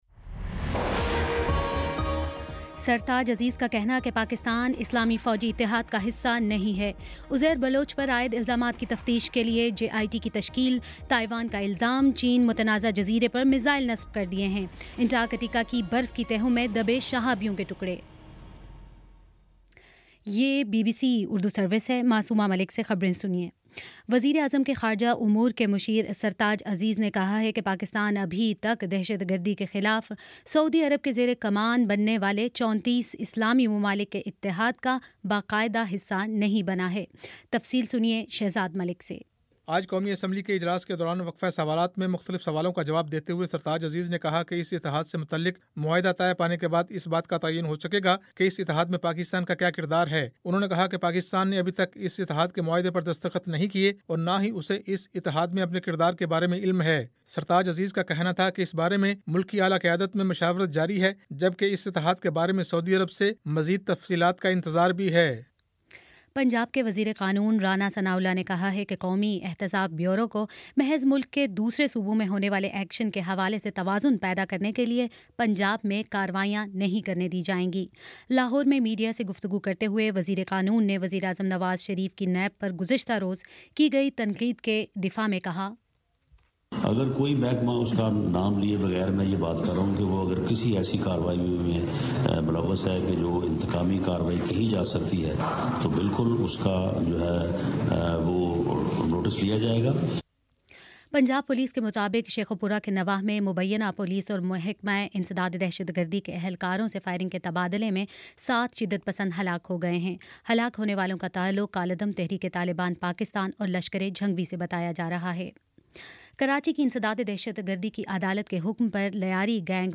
فروری 17 : شام چھ بجے کا نیوز بُلیٹن